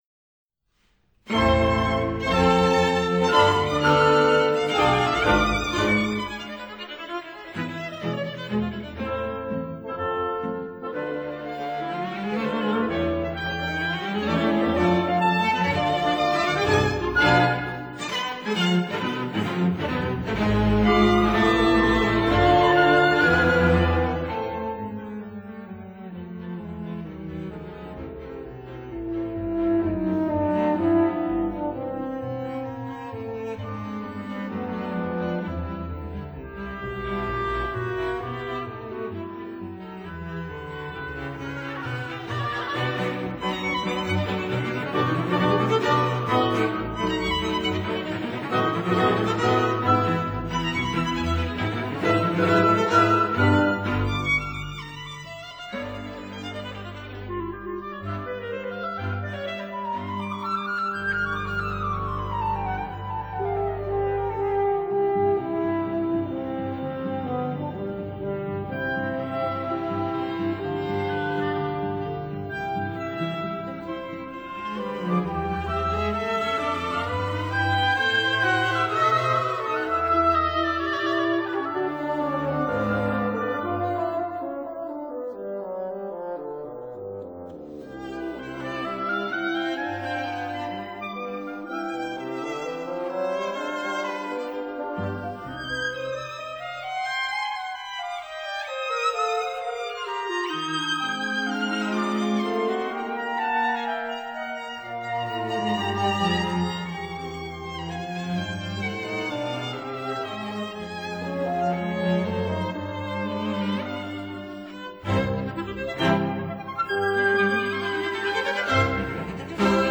flute
oboe
clarinet
horn
bassoon
violin
viola
cello
double bass